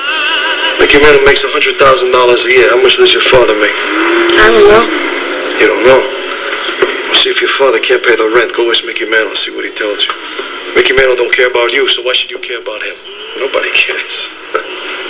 Mantle - (Chazz From Bronx Tale, Mickey Mantle don't care speech) 325KB